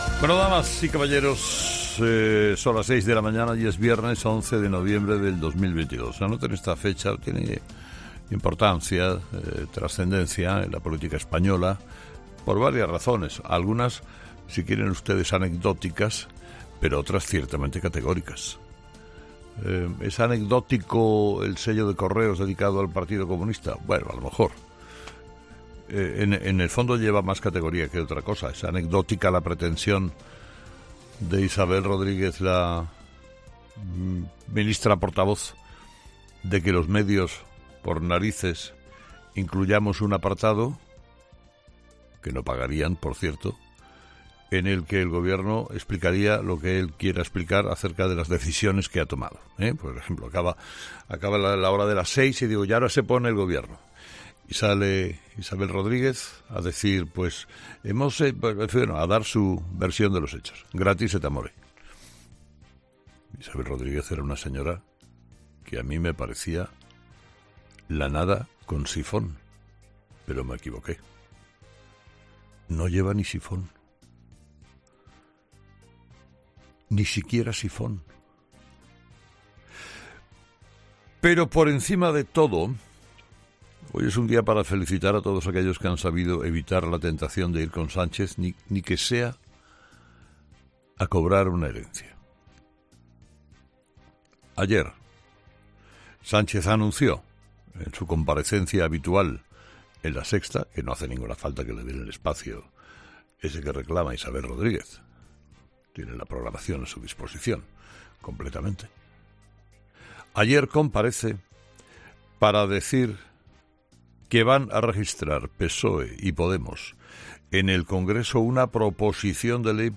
Carlos Herrera repasa los principales titulares que marcarán la actualidad de este viernes 11 de noviembre en nuestro país
Carlos Herrera, director y presentador de 'Herrera en COPE', ha comenzado el programa de este viernes analizando las principales claves de la jornada, que pasan, entre otros asuntos, por la reforma del delito de sedición que va a llevar a cabo el Gobierno.